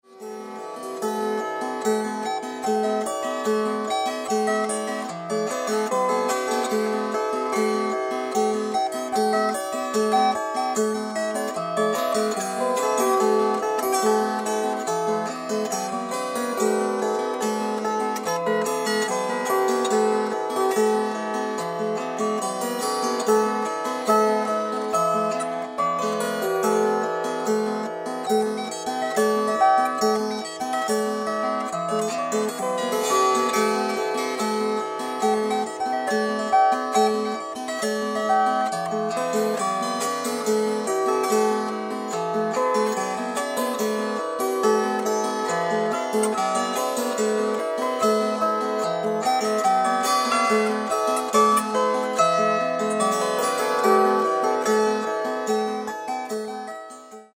(metallic gothic harp)  3'203.05 MB1.70 Eur